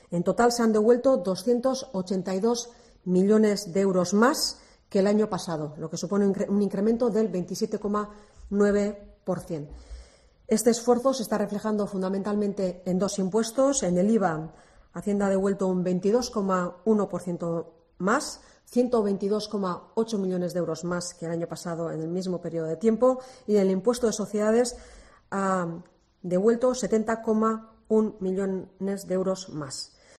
Eider Mendoza, portavoz de la Diputación de Gipuzkoa